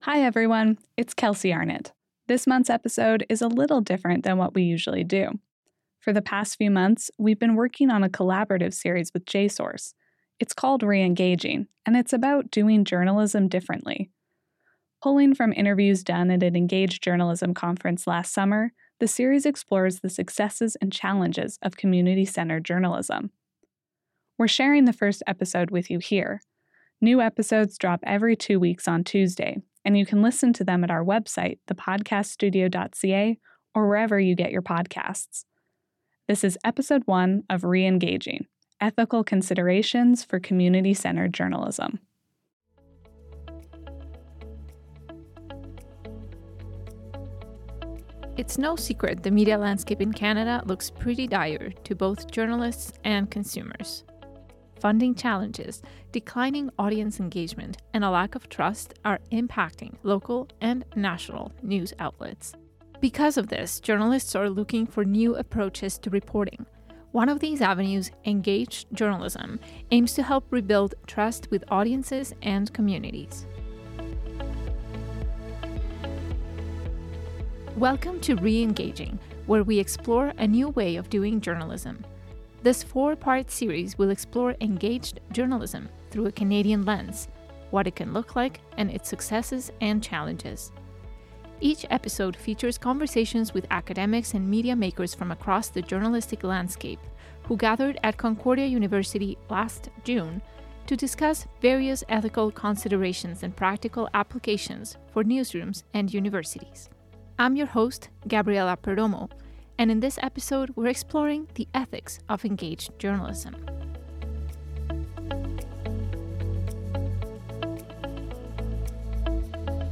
The series draws on interviews from an Enagaged Journalism conference at Concordia University last summer, and looks at the various successes and challenges of community-centred journalism. This first episode is all about ethical considerations.